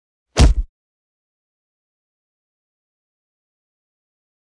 赤手空拳击中肉体－低频3-YS070524.wav
通用动作/01人物/03武术动作类/空拳打斗/赤手空拳击中肉体－低频3-YS070524.wav